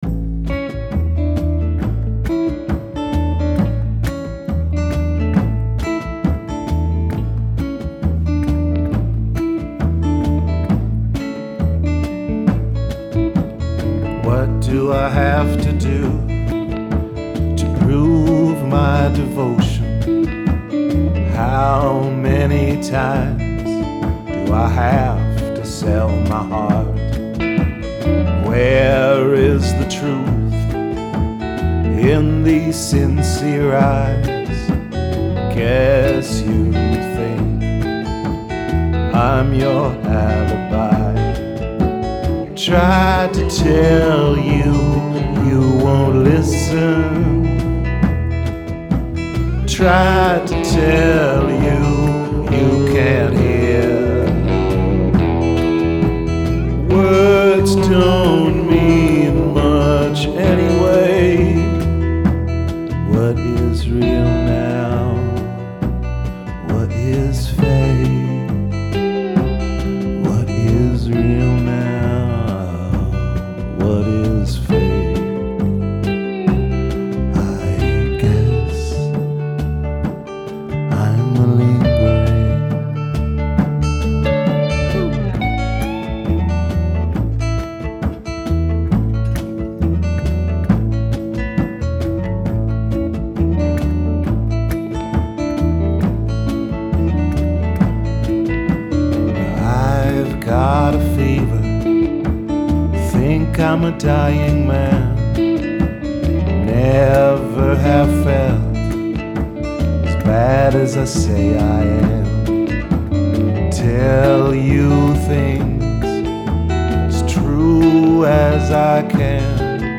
Rehearsals 13.3.2012